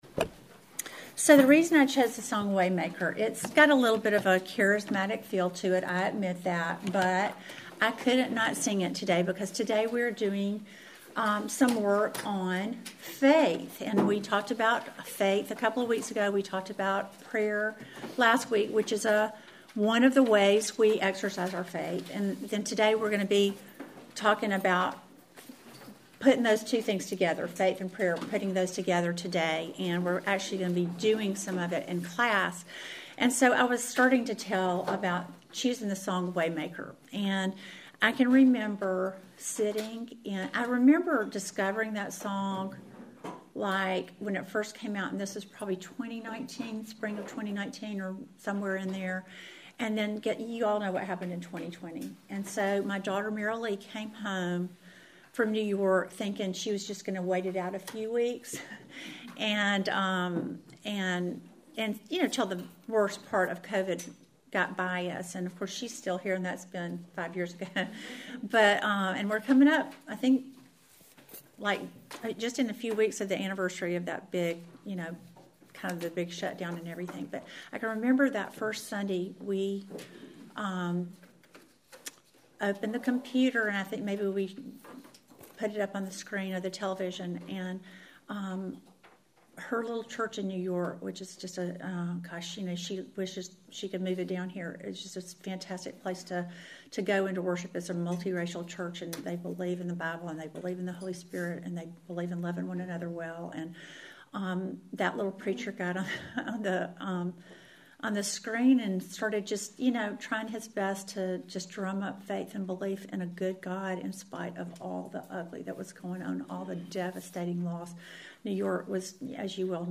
Welcome to the fourteenth lesson in our series GOD’S LIVING AND ACTIVE WORD!